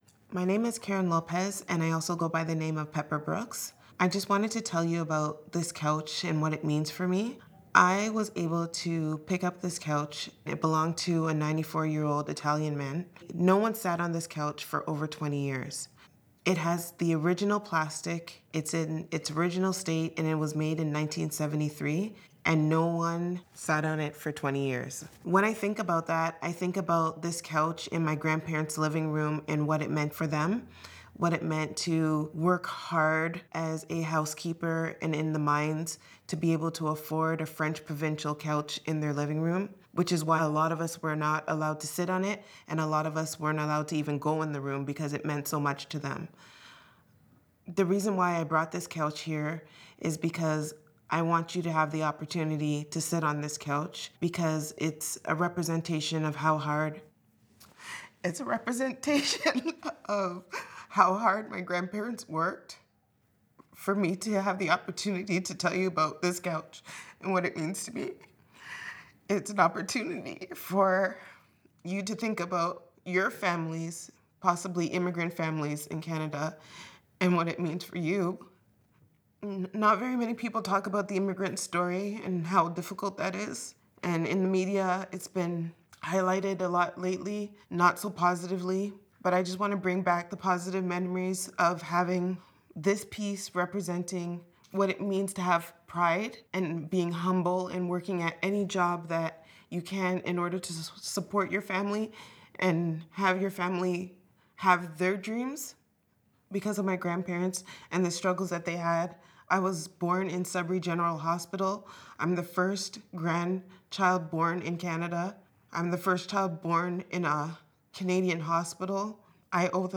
In the interviews below, the curators speak about the significance of their chosen object.